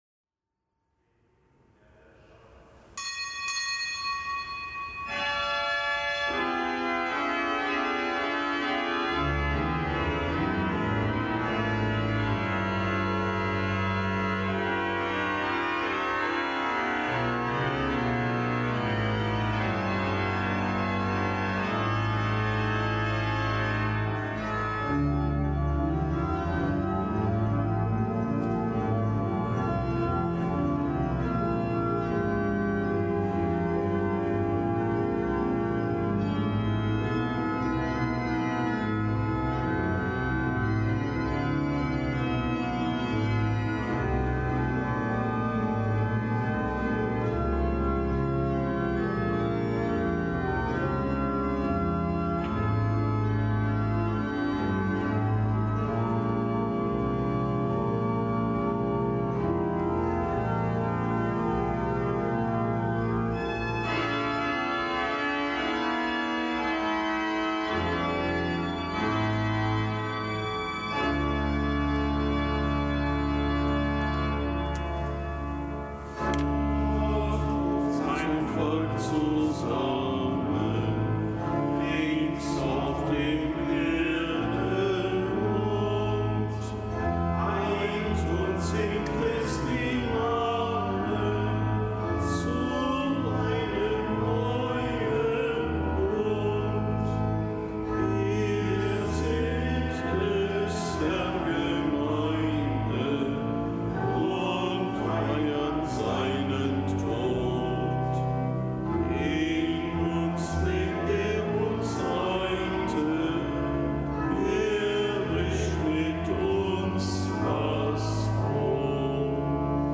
Kapitelsmesse am Mittwoch der siebten Woche im Jahreskreis
Kapitelsmesse aus dem Kölner Dom am Mittwoch der siebten Woche im Jahreskreis.